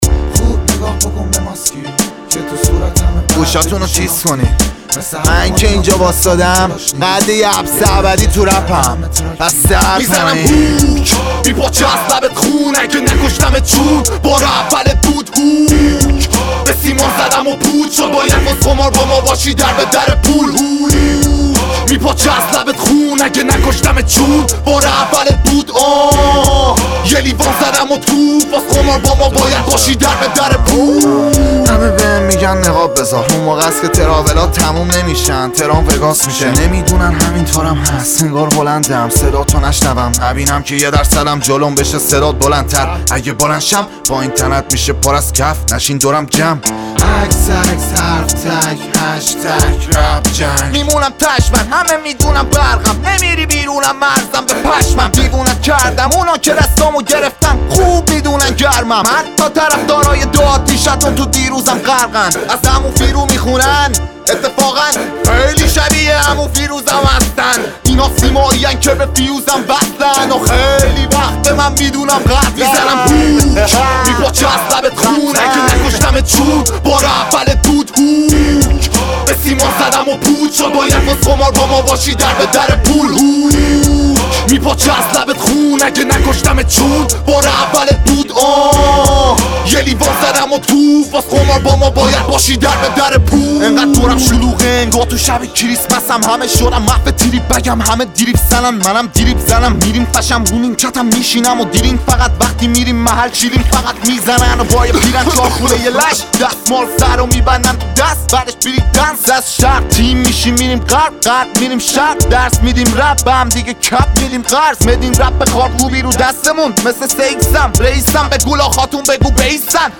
شاد و پرانرژی